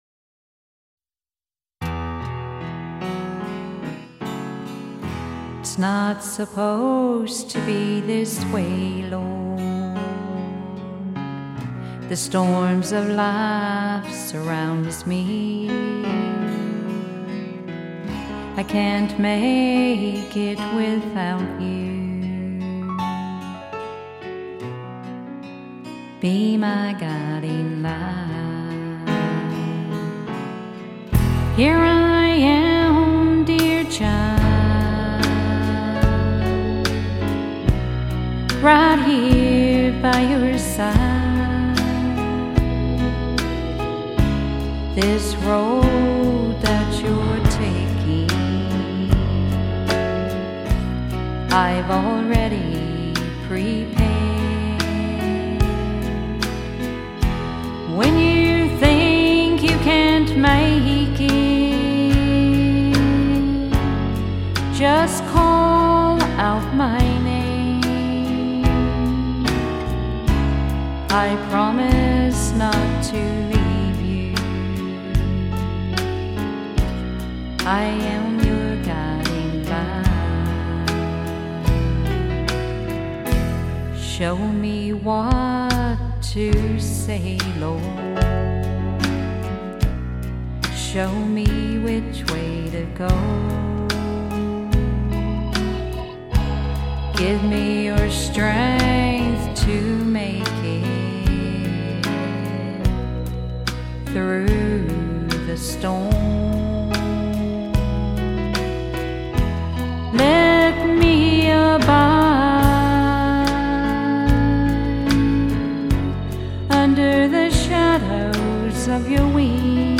southern country and bluegrass songs